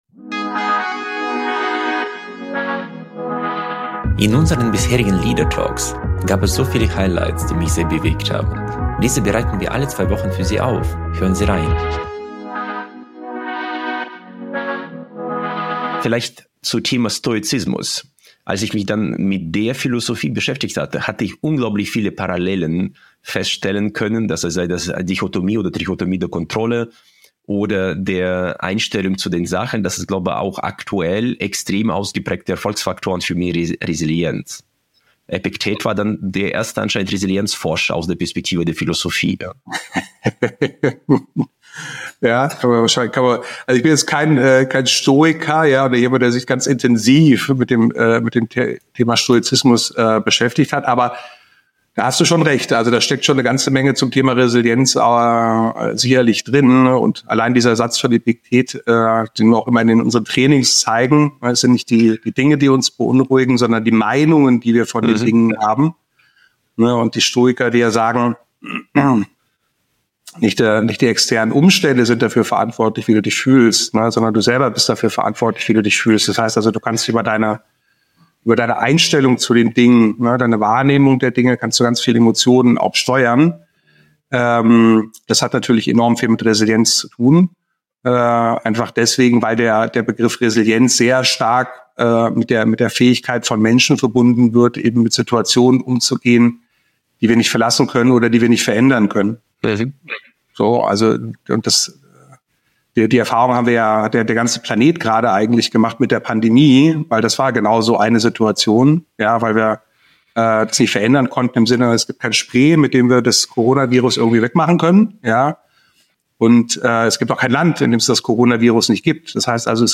Ein Gespräch über Stoizismus, die Kraft der Resilienz und das ‚Warum zum Leben‘.